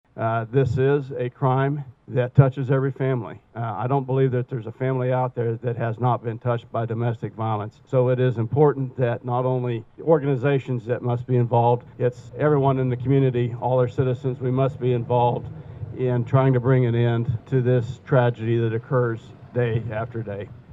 A Silent Witness Ceremony was held at the Riley County Courthouse Plaza Wednesday over the lunch hour.
Riley County Attorney Barry Wilkerson addresses the gathering Wednesday outside his office.